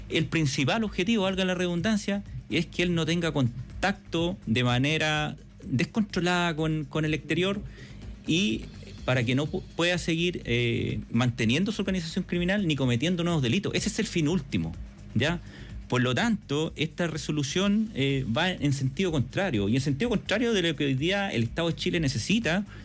En conversación con Radio Bío Bío, el subdirector operativo (s) de Gendarmería, Luis González, criticó la medida y enfatizó que va contra los intereses del Estado.